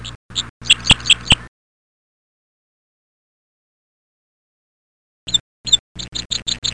chirps.mp3